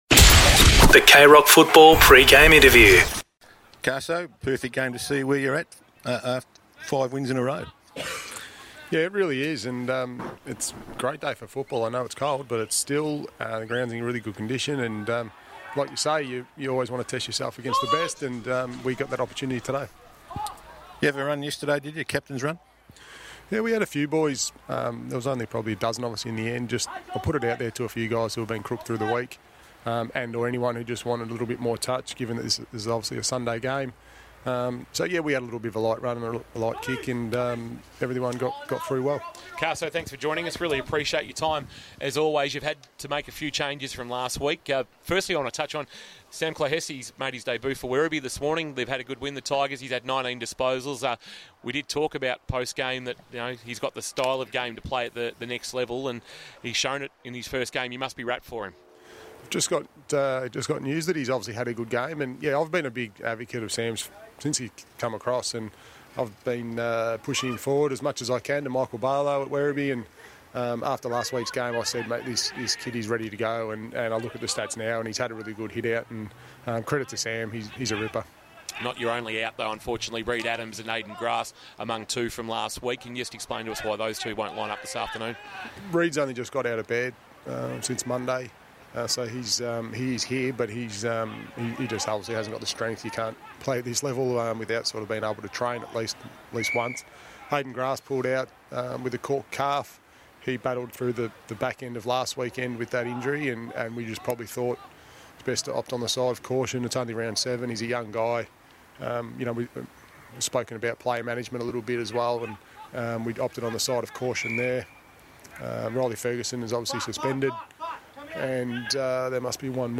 2022 - GFL ROUND 7 - ST MARY'S vs. ST JOSEPH'S: Pre-match Interview